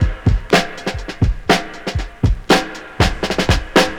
• 120 Bpm Drum Loop E Key.wav
Free breakbeat - kick tuned to the E note. Loudest frequency: 1270Hz
120-bpm-drum-loop-e-key-1wT.wav